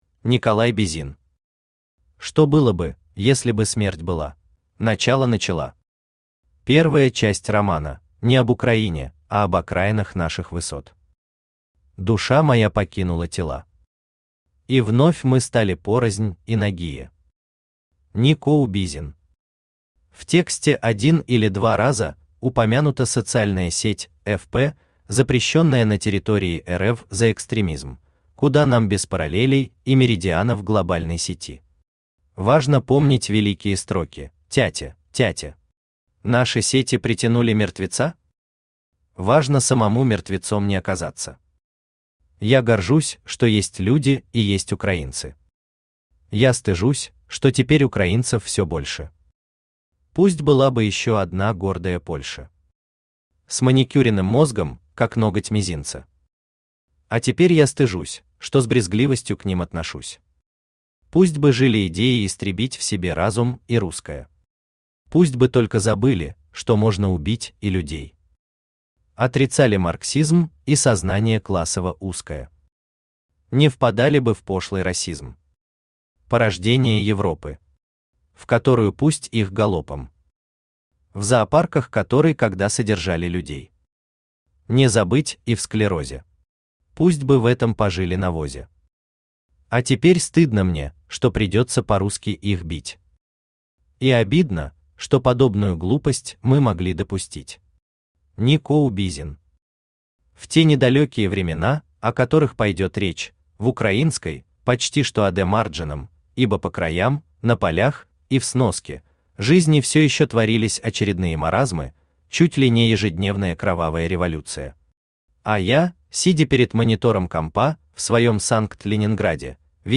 Аудиокнига Что было бы, если бы смерть была | Библиотека аудиокниг
Aудиокнига Что было бы, если бы смерть была Автор Николай Бизин Читает аудиокнигу Авточтец ЛитРес.